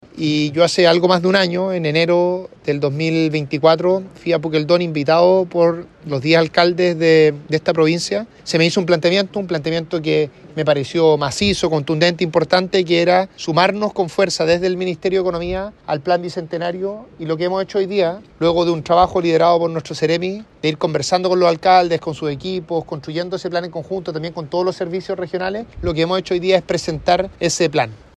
ministro-economia.mp3